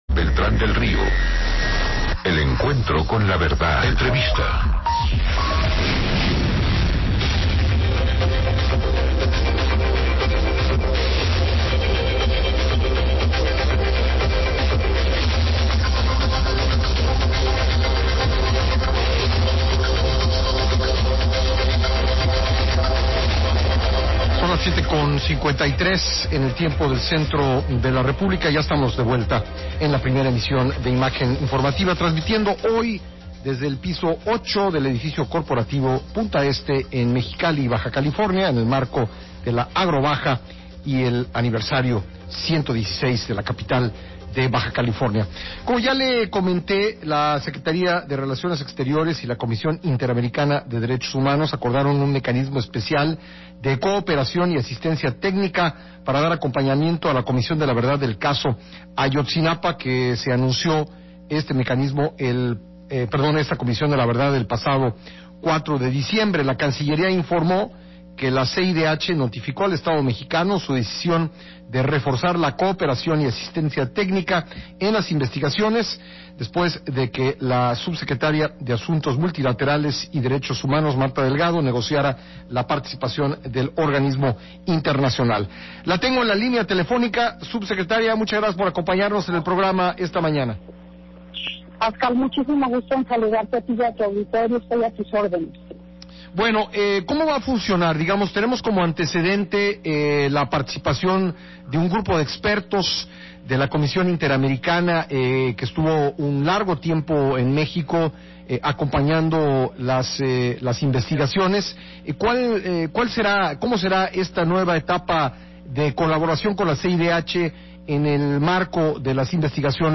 [Audio] Entrevista en Imagen Radio con Pascal Beltrán del Río sobre colaboración con CIDH en Caso Ayotzinapa